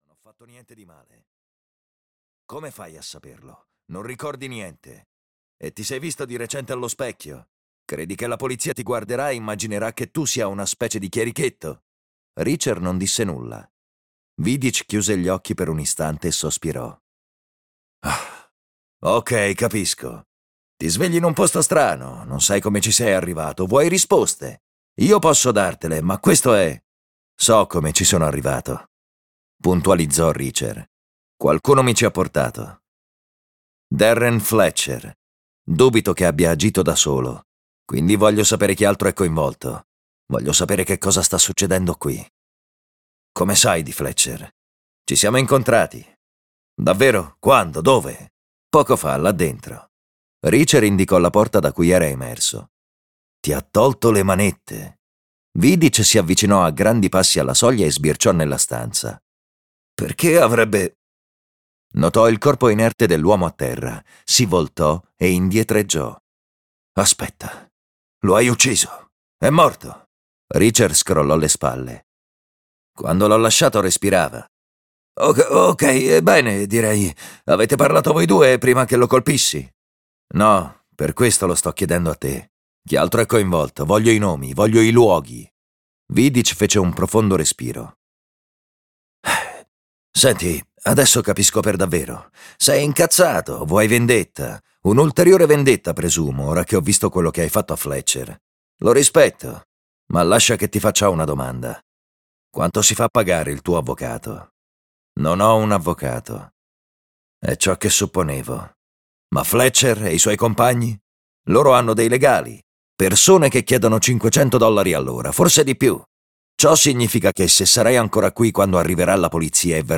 "Senza uscita" di Lee Child - Audiolibro digitale - AUDIOLIBRI LIQUIDI - Il Libraio